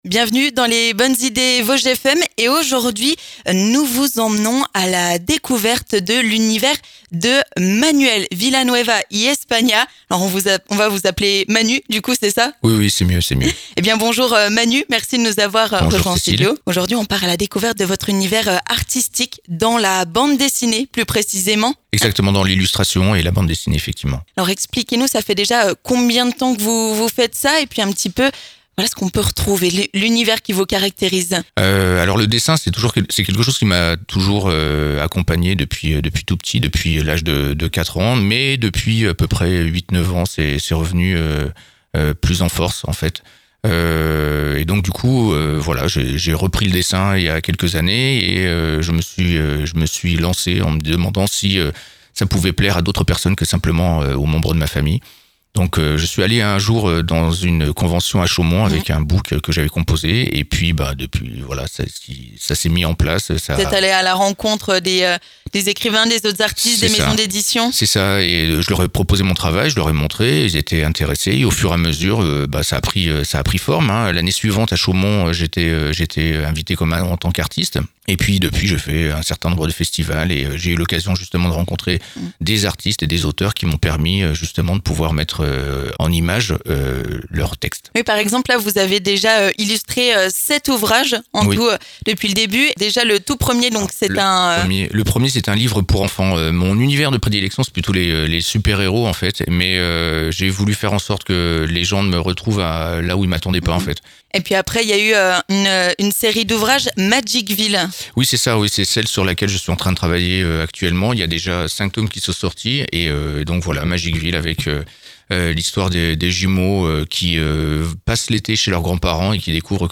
Il est venu en studio pour nous présenter son univers, comment il est tombé dans la bande-dessinée.